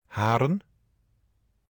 Haren (French: [aʁœn] ; Dutch: [ˈɦaːrə(n)]
Nl-Haren.oga.mp3